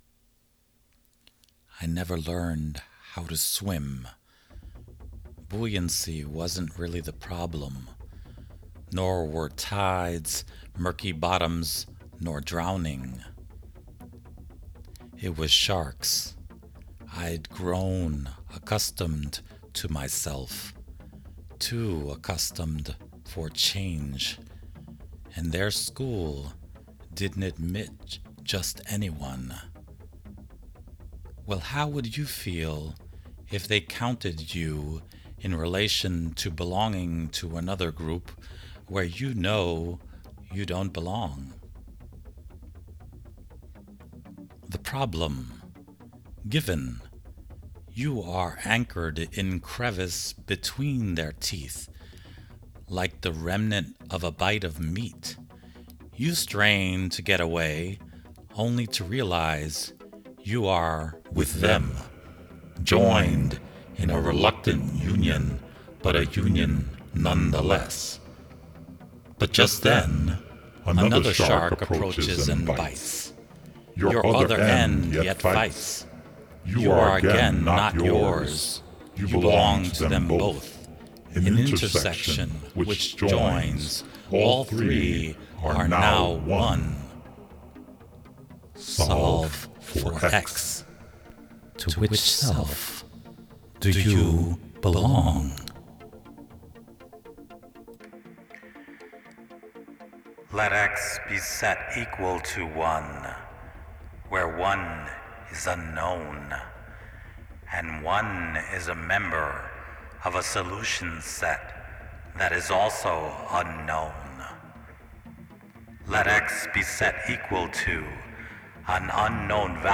Let X Equal 1 is the audio version of a visual poem I created and published on the my YouTube channel shown below. I have also added some additional audio content as the ending commentary within this audio version.